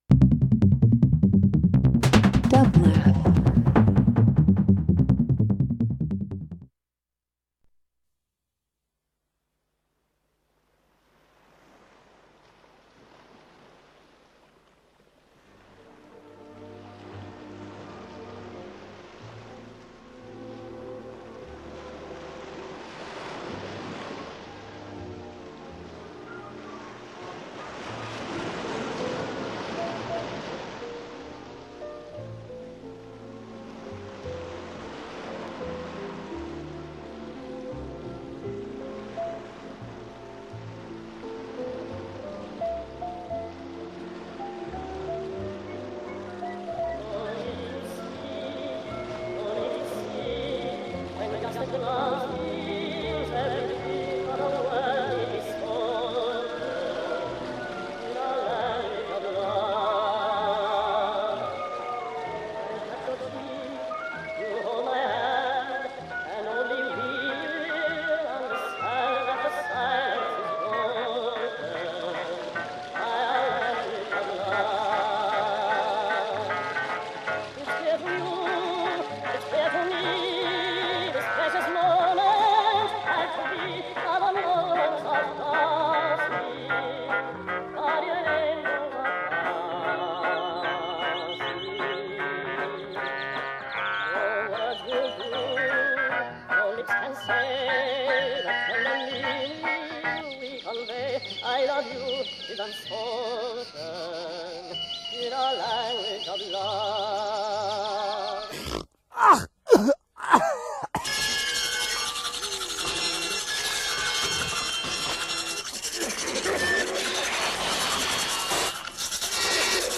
an LA 4-piece with a 9th ear
Experimental
Punk